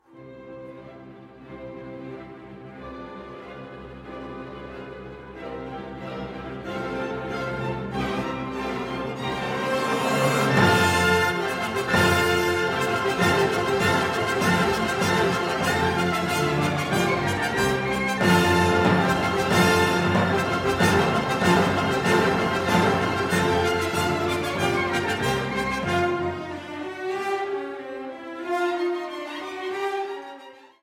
New Jersey Symphony
A master storyteller, Beethoven instantly captures the mood of Goethe’s play about resisting oppression in this taut and thrilling overture.